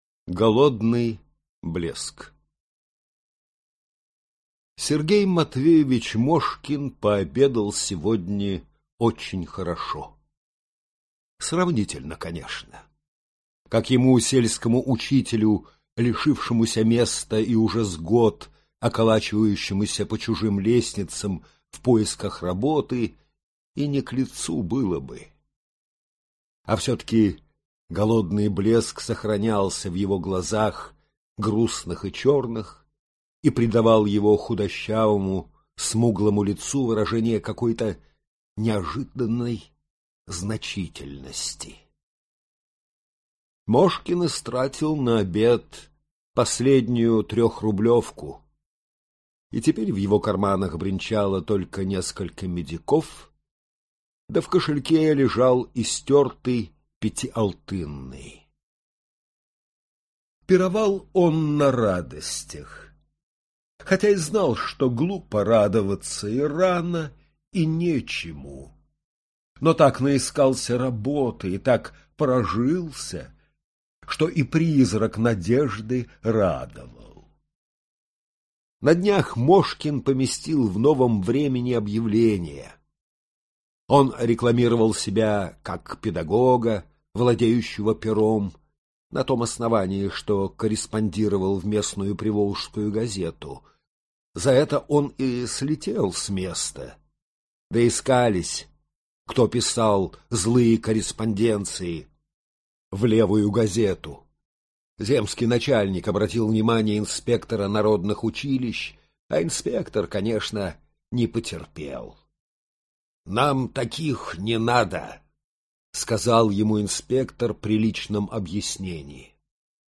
Аудиокнига Красногубая гостья | Библиотека аудиокниг